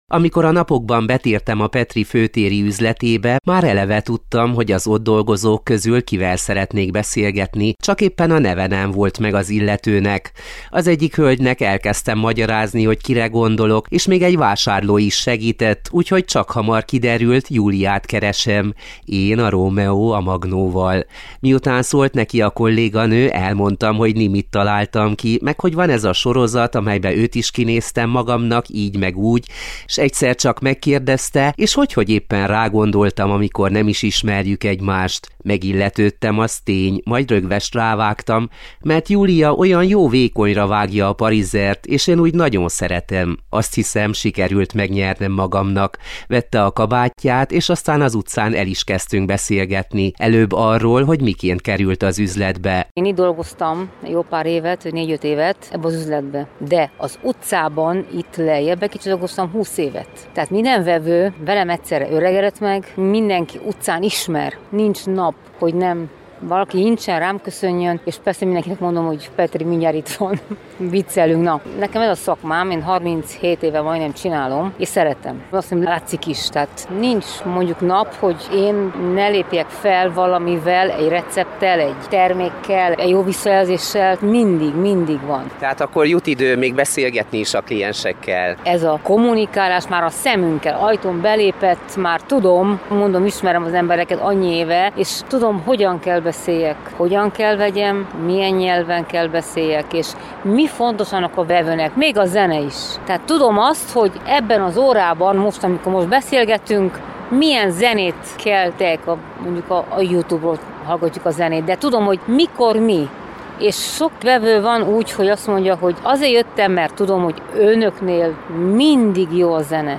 Ezúttal egy kedves párbeszéd előzte meg a pillanatot, amikor a magnó előkerülhetett.